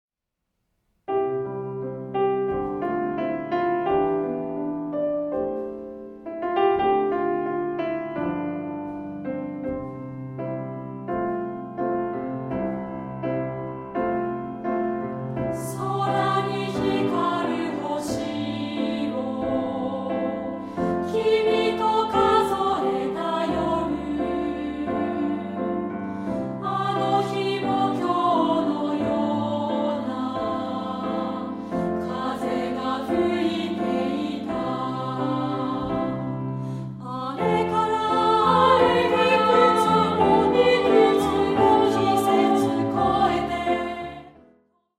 範唱＋カラピアノCD付き
2部合唱／伴奏：ピアノ